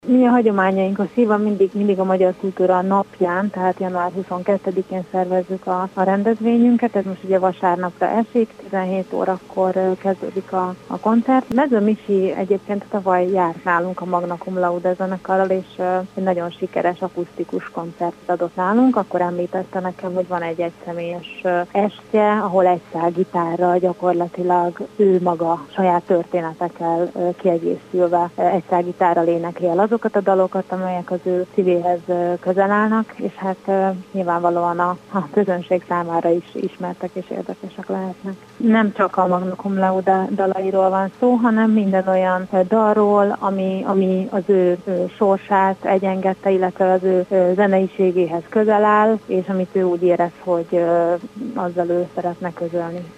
A városvezetés ragaszkodik ahhoz, hogy a bevásárlóközpontoknál lévő körforgalomból induljon egy elkerülő út, amely egy felüljárón vezetné át a forgalmat a vasúti pálya felett és a takarmánygyártó cég mögött csatlakozna a Szabadság útba. Kőszegi Zoltán polgármester indokolta a döntést.